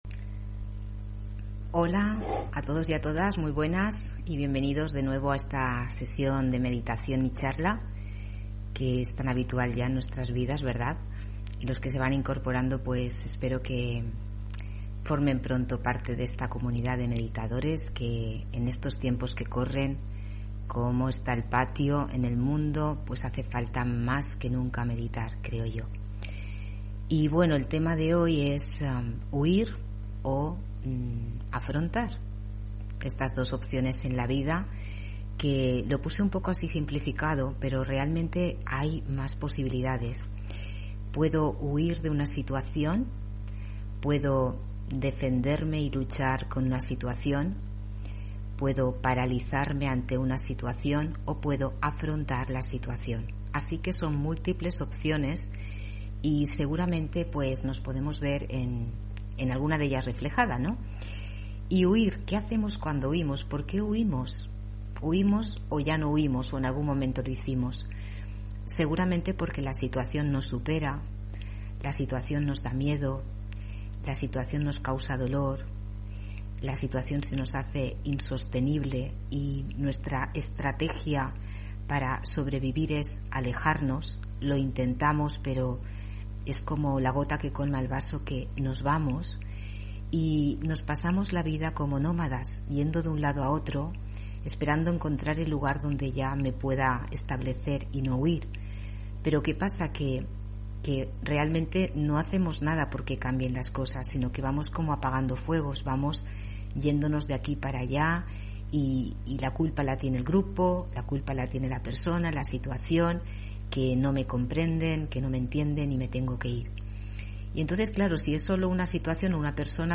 Meditación y conferencia: ¿Huir o afrontar?